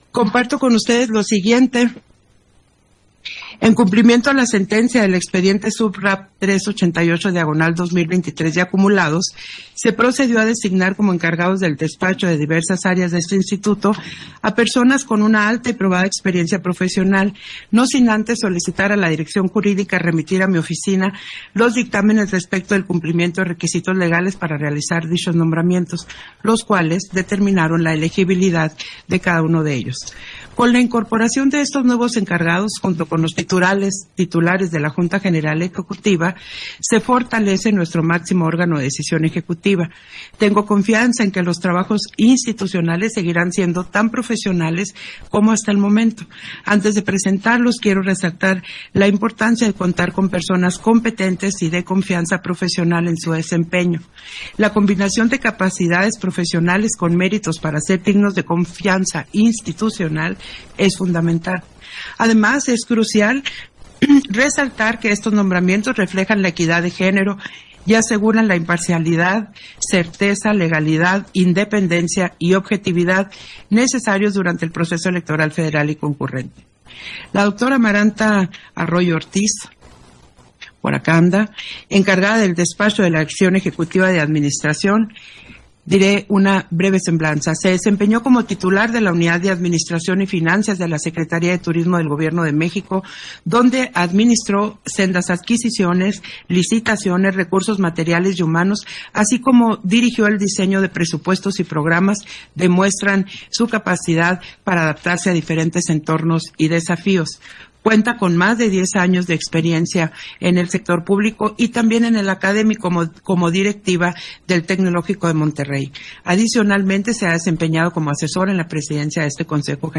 290124_AUDIO_INTERVENCIÓN-CONSEJERA-PDTA.-TADDEI-SESIÓN-EXTRAORDINARIA-JGE - Central Electoral